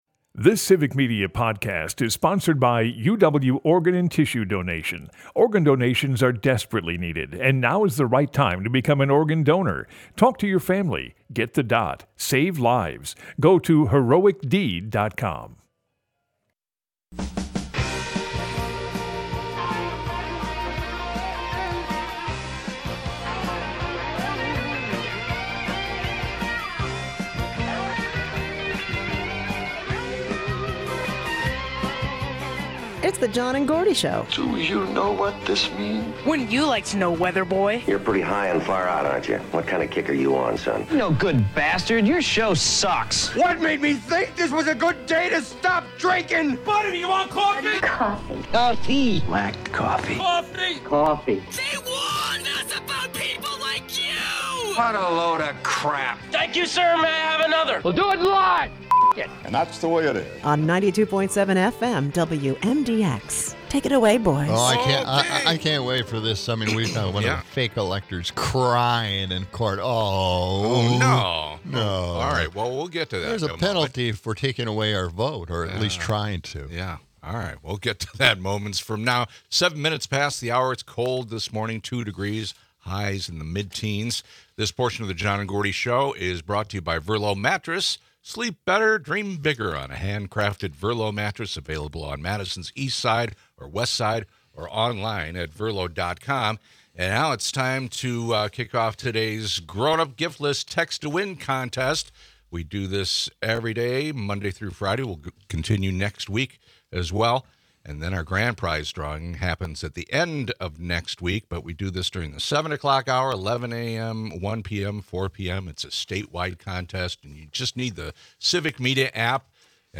Broadcasts live 6 - 8am weekdays in Madison.
Wrapping up, the discussion of speeding gets heated, as we look back at the guy's discussion of the city's decision to lower residential speed limits.